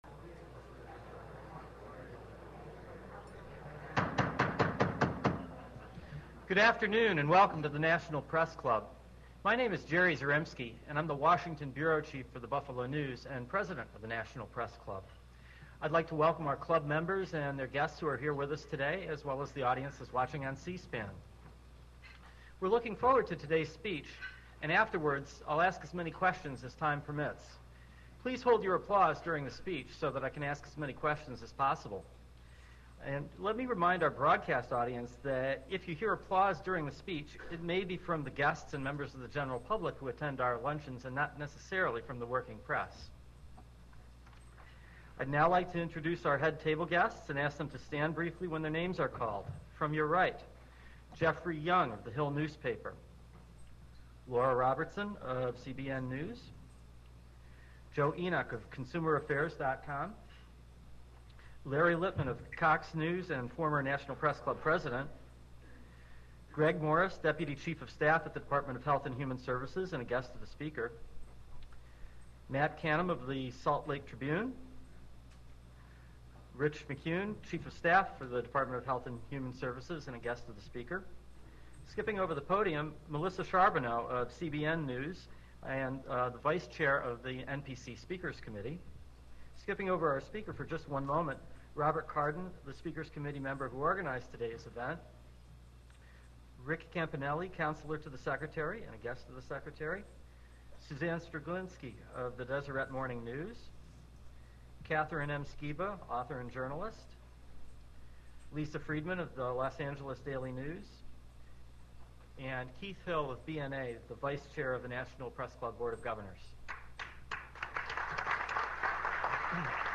On December 19, 2007 Leavitt gave a speech at the National Press Club. The subject of the speech was supposed to be food safety but what he really talked about was supply chain management.